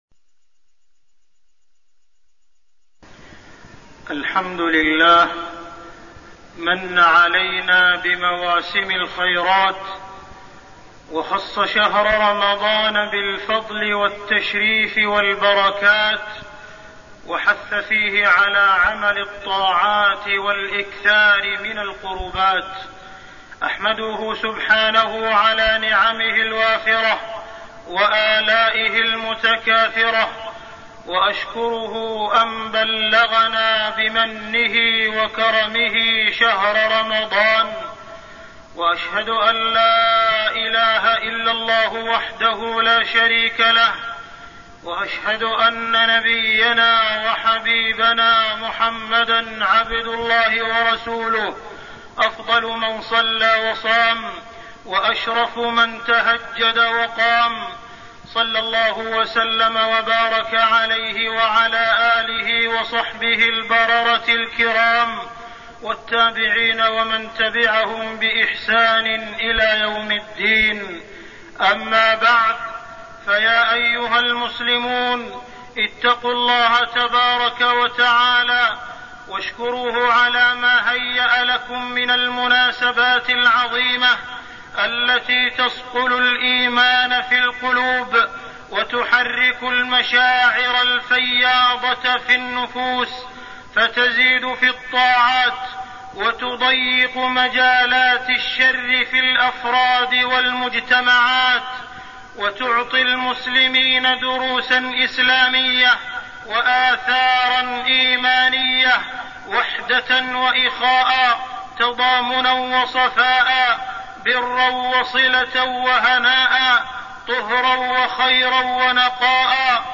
تاريخ النشر ٦ رمضان ١٤١٦ هـ المكان: المسجد الحرام الشيخ: معالي الشيخ أ.د. عبدالرحمن بن عبدالعزيز السديس معالي الشيخ أ.د. عبدالرحمن بن عبدالعزيز السديس حفظ الجوارح في رمضان